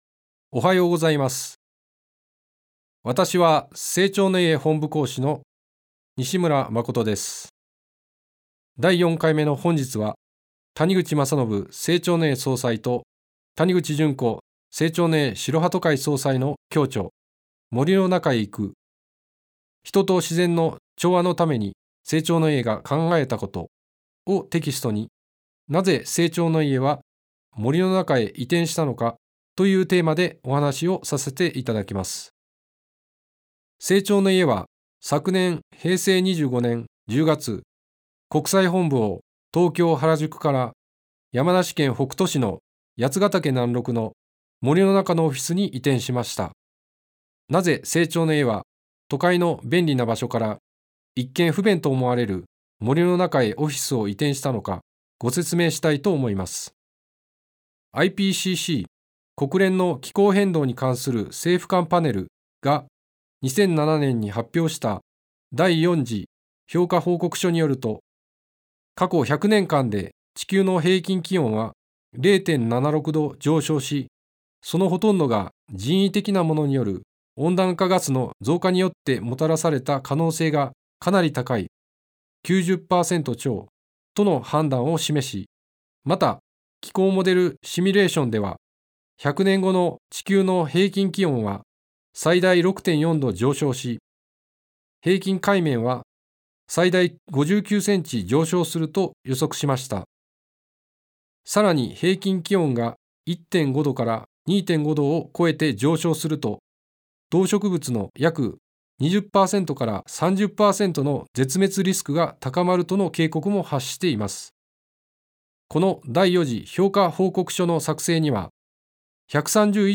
生長の家がお届けするラジオ番組。
生長の家の講師が、人生を豊かにする秘訣をお話しします。